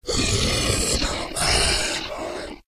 Divergent / mods / Soundscape Overhaul / gamedata / sounds / monsters / psysucker / idle_2.ogg